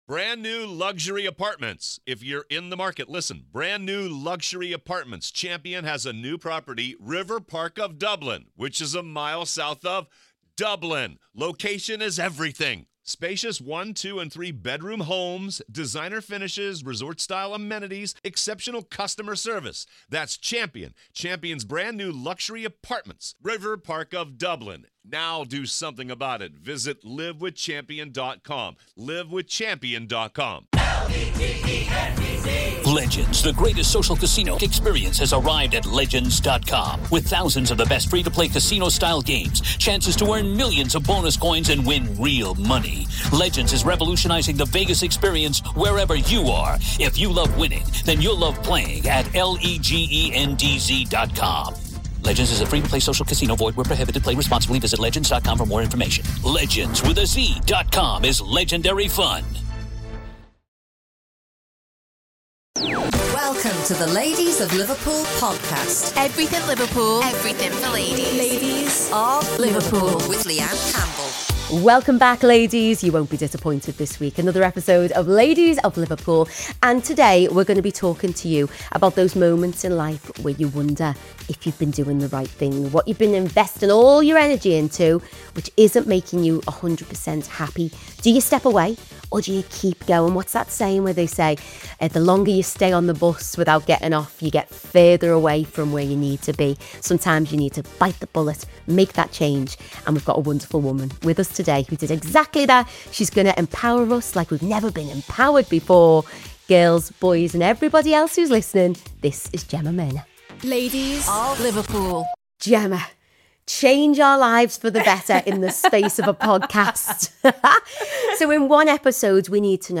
In this deeply honest conversation